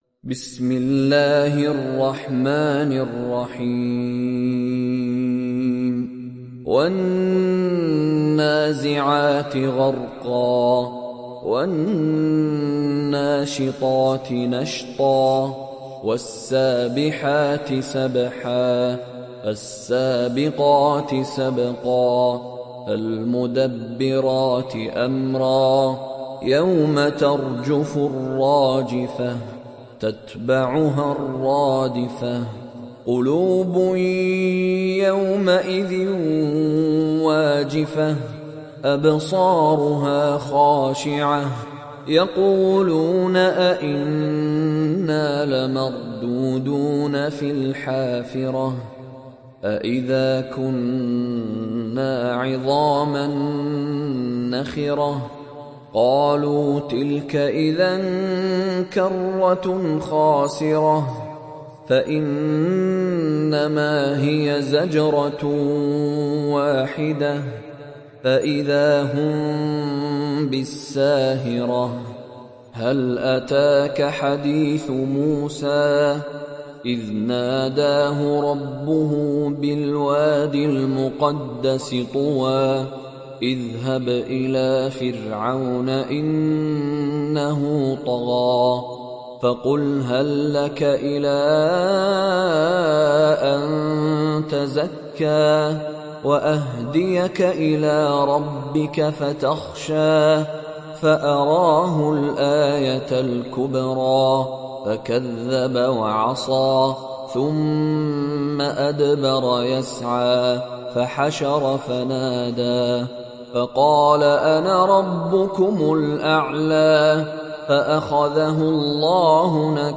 Surah An Naziat mp3 Download Mishary Rashid Alafasy (Riwayat Hafs)
Surah An Naziat Download mp3 Mishary Rashid Alafasy Riwayat Hafs from Asim, Download Quran and listen mp3 full direct links